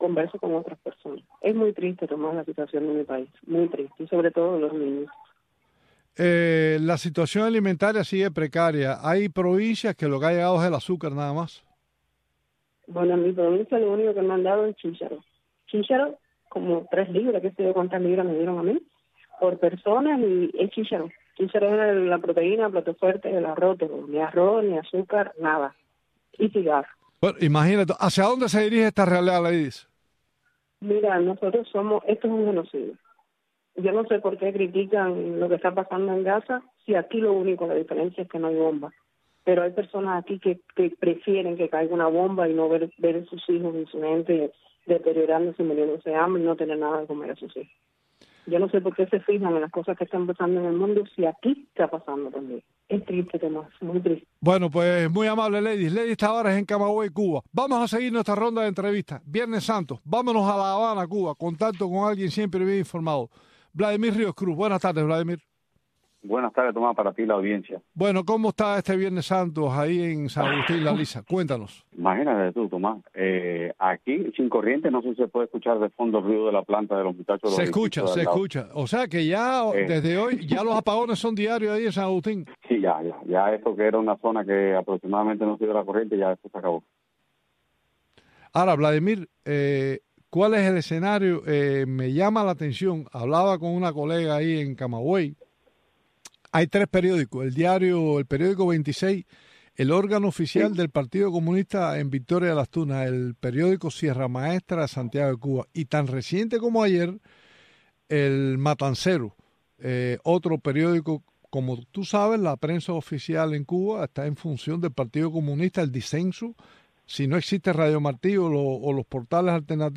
en este espacio informativo en vivo